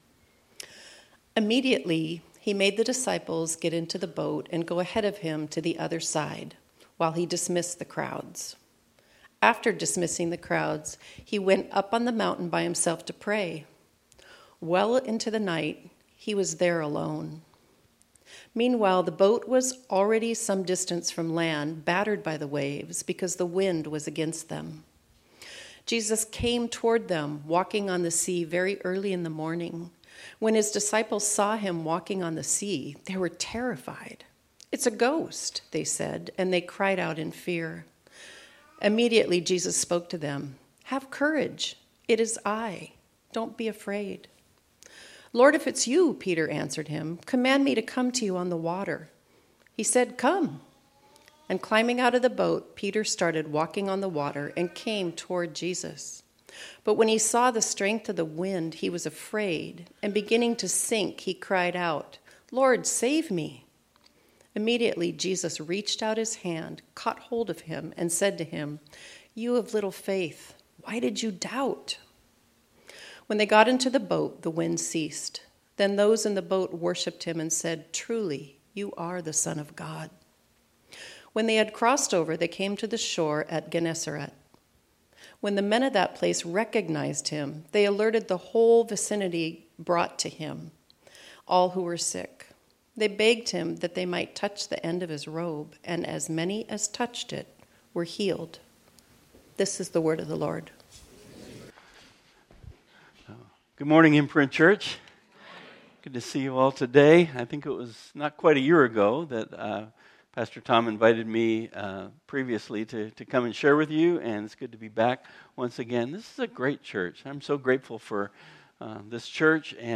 This sermon was originally preached on Sunday, June 30, 2024.